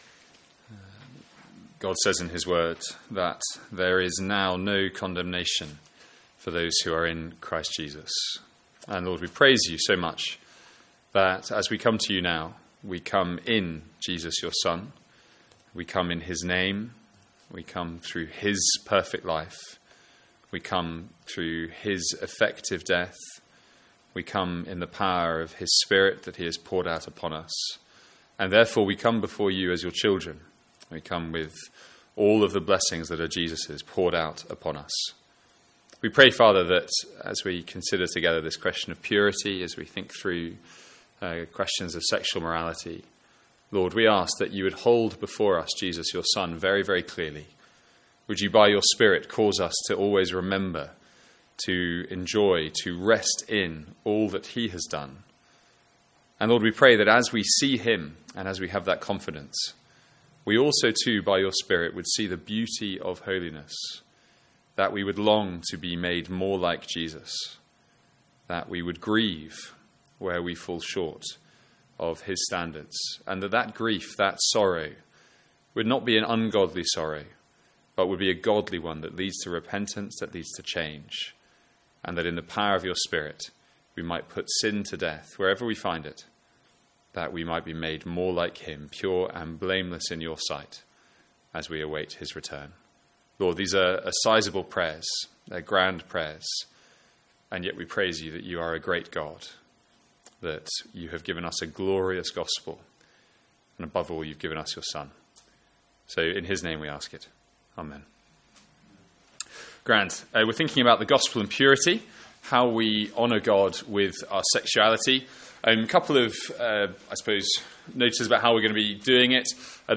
Sermons | St Andrews Free Church
From our student Mid-Year Conference.